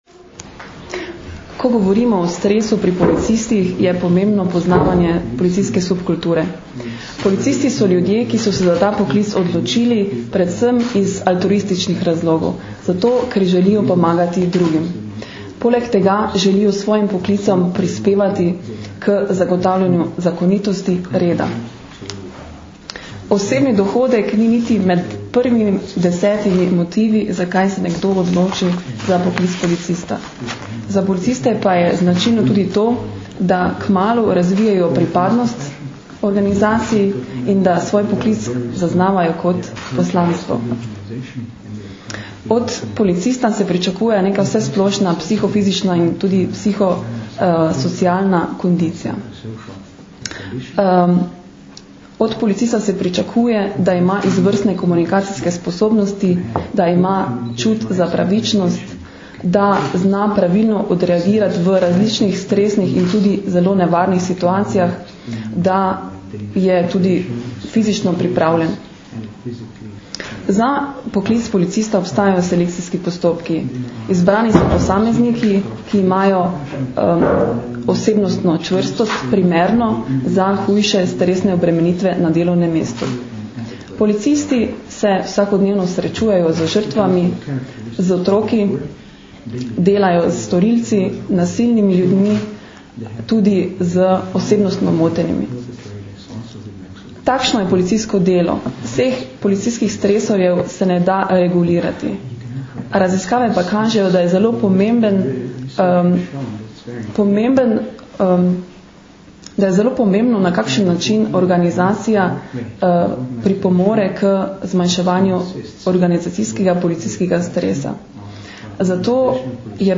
Policija - Psihološka pomoč in zaščita policistov - informacija z novinarske konference